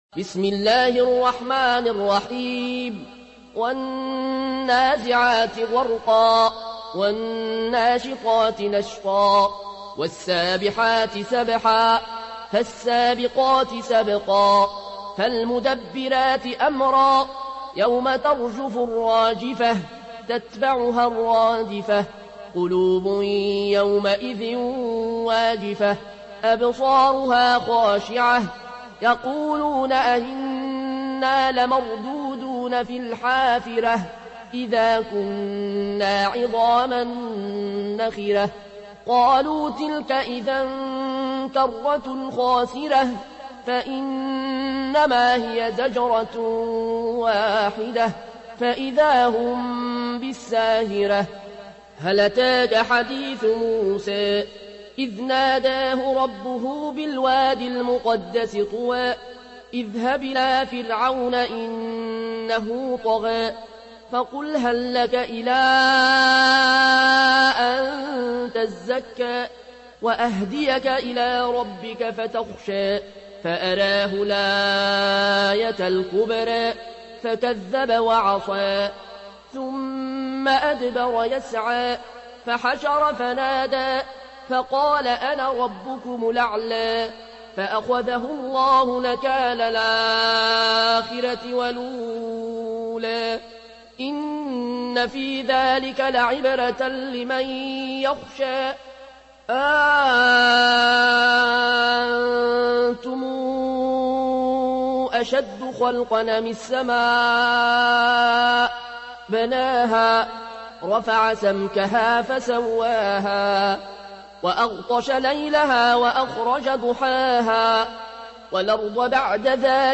Récitation